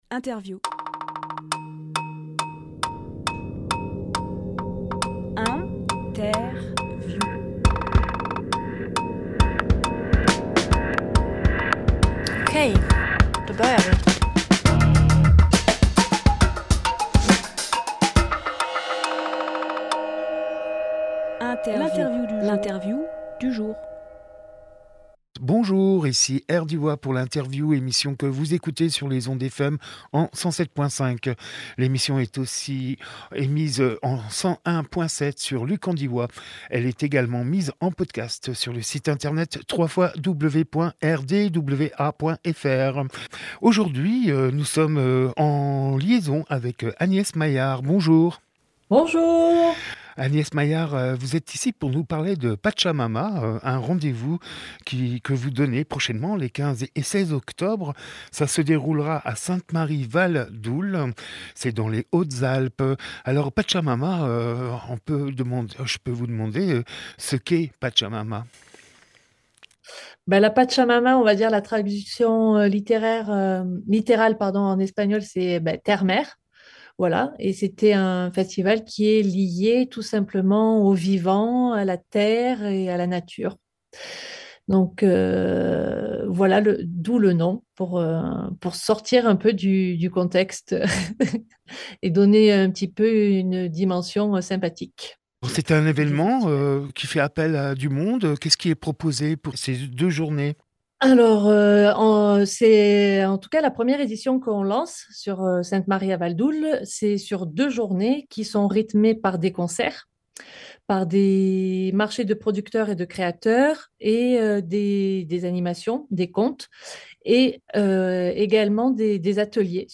Emission - Interview Pachamama à Sainte-Marie Valdoule Publié le 10 octobre 2022 Partager sur…
29.09.22 Lieu : Studio RDWA Durée